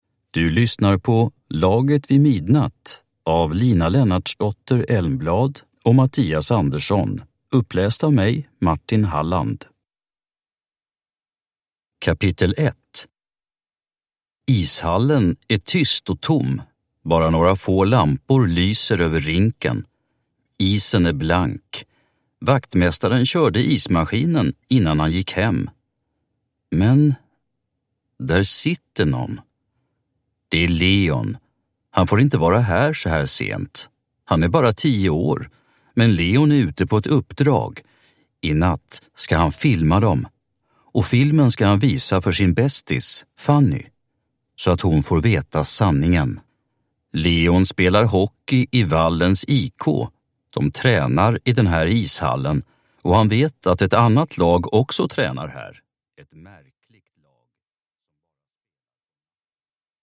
Laget vid midnatt – Ljudbok